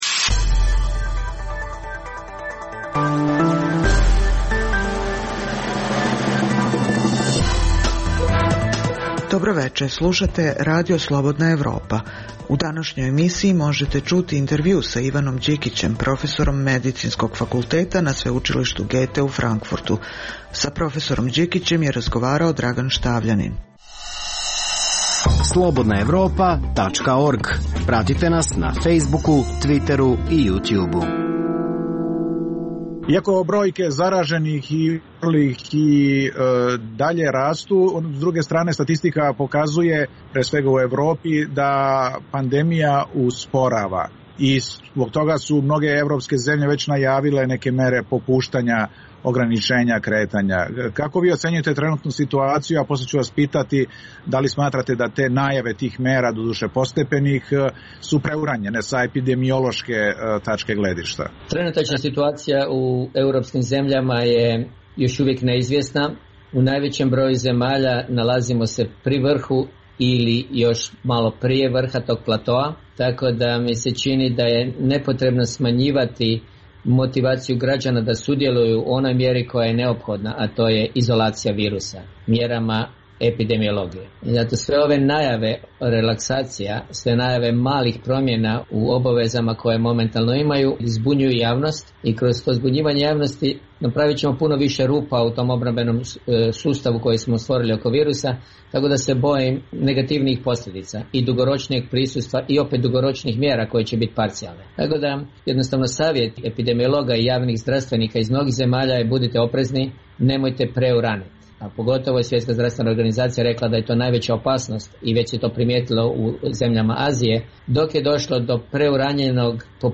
U današnjoj emisiji možete poslušati intervju sa Ivanom Đikićem, profesorom medicinskog fakulteta na Sveučilištu Goethe u Frankfurtu, te Radio magazin.